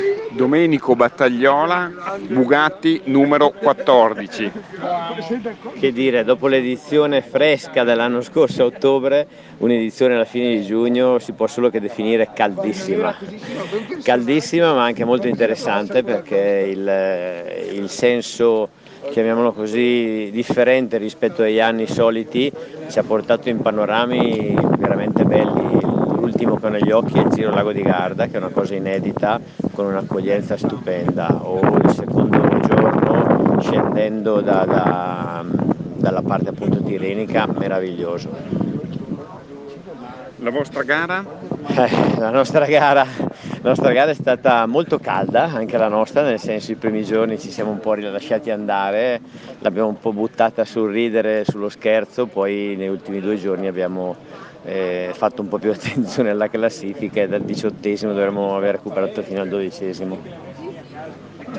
L'INTERVISTA AUDIO